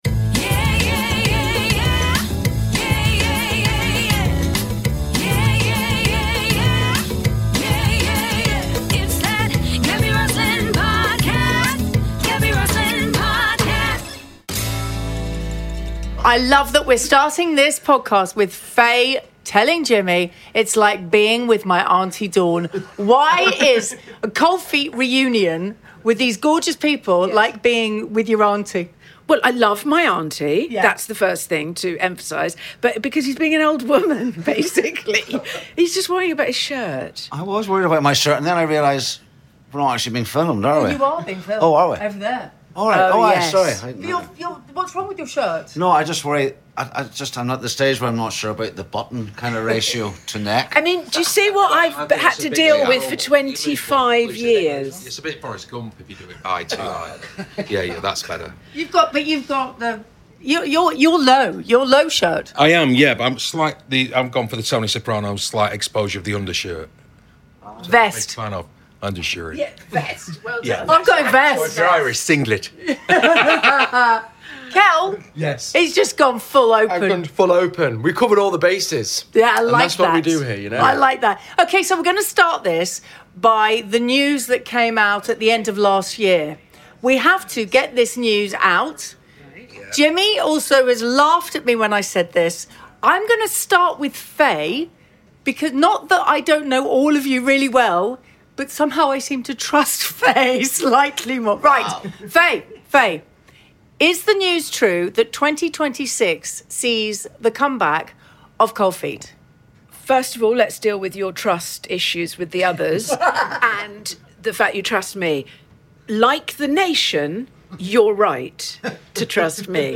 Gaby brought Jimmy Nesbitt, Fay Ripley, John Thomson and Cel Spellman together to find out...
They sat down at The Frog restaurant in Covent Garden, London, ahead of a charity lunch (for Children With Cancer) to discuss the show, their friendship and whether there could be....possibly...another series on the horizon!